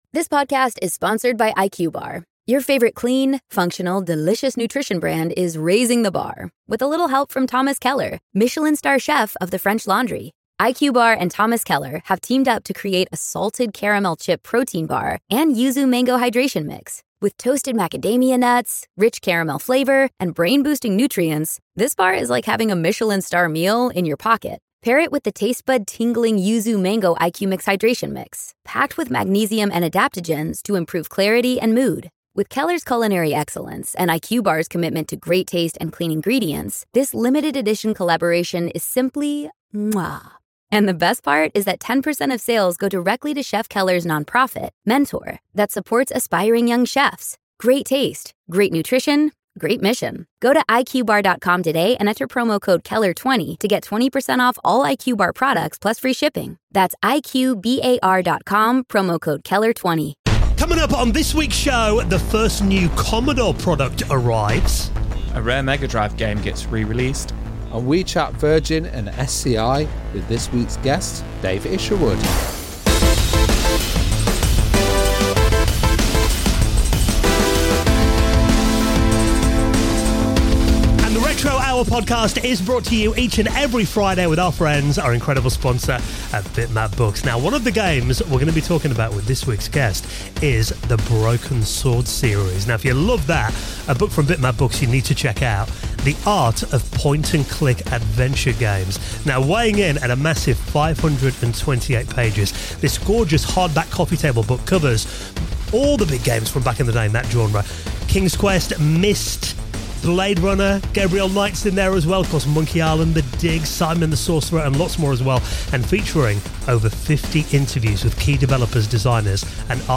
00:00 - The Week's Retro News Stories